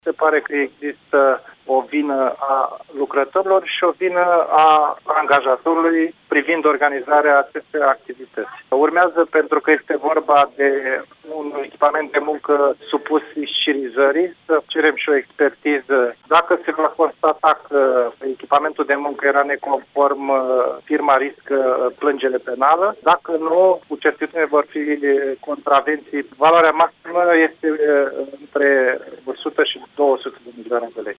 UPDATE 14:00 – Inspectoratul Teritorial de Muncă, dar şi Poliţia au început o anchetă pentru a stabili cauzele accidentului. Directorul ITM Iaşi, Andrei Albulescu: